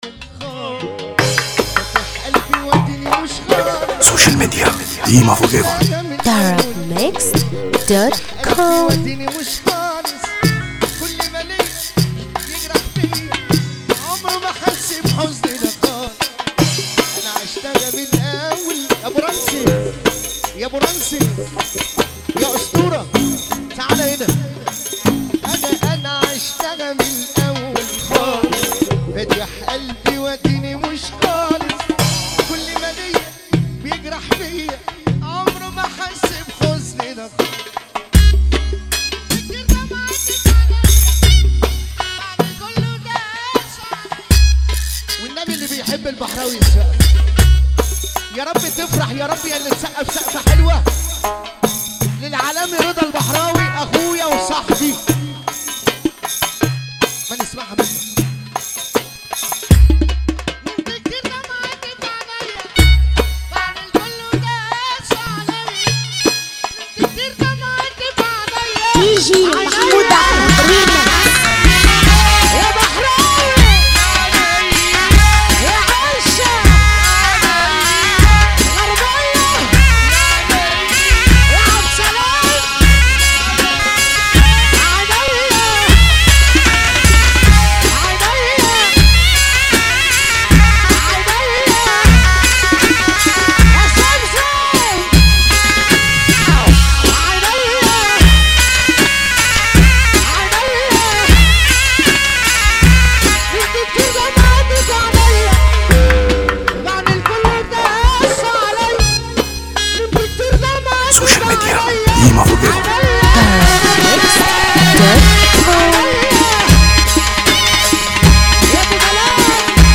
درامز